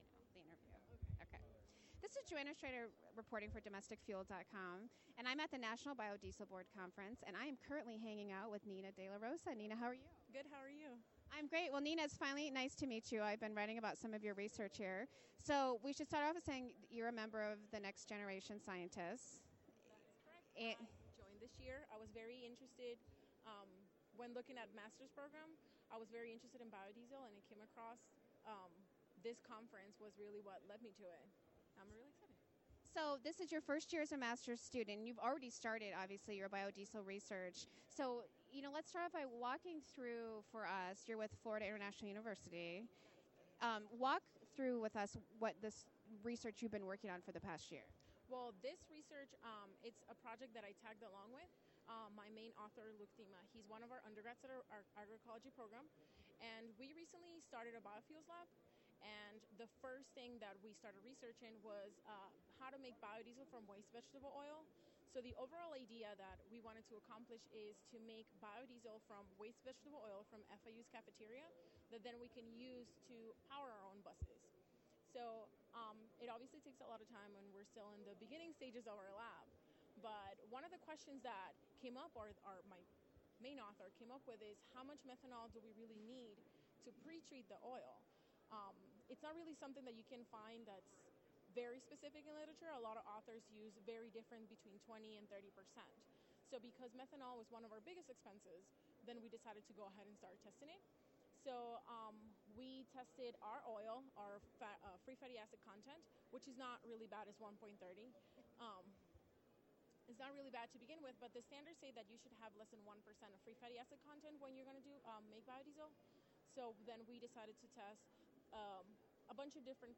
Here are several interviews with the Next Generation Scientists that discuss their research, why they became involved in the program, and advice for students who are still looking for their niche.